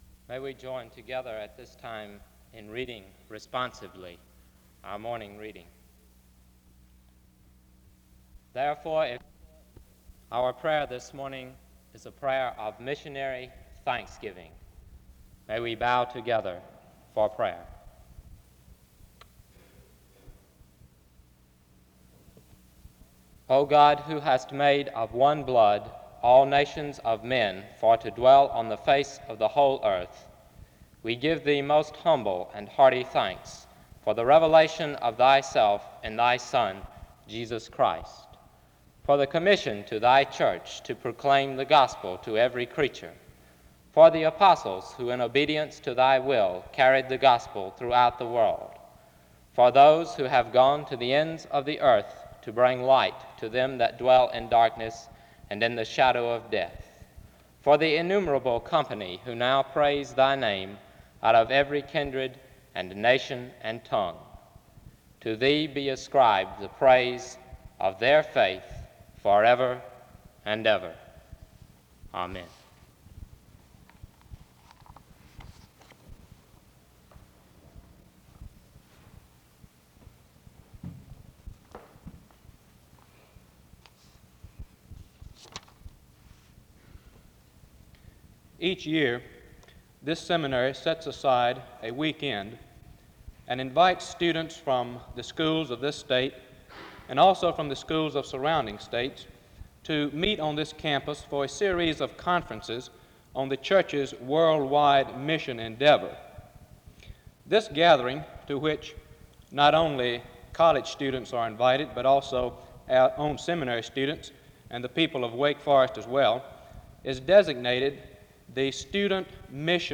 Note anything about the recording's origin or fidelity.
The service begins with a word of prayer from 0:00-1:12. SEBTS Chapel and Special Event Recordings SEBTS Chapel and Special Event Recordings